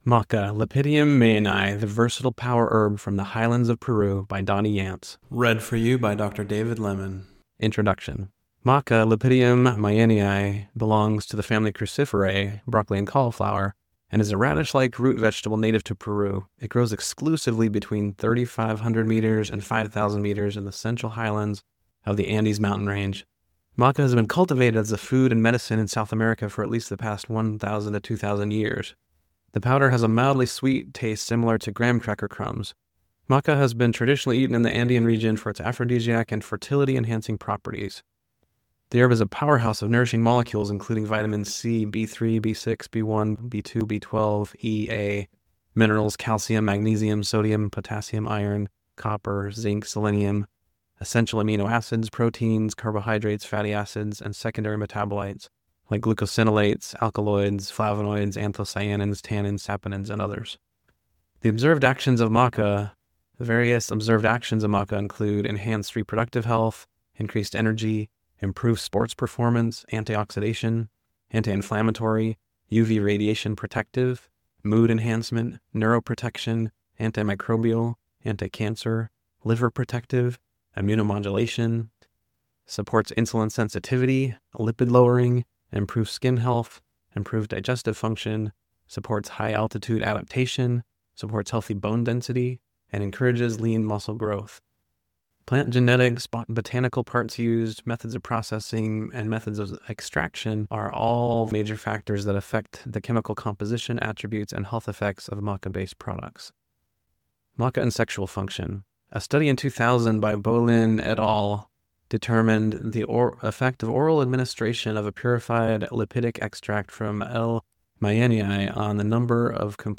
Maca Audio Narration 2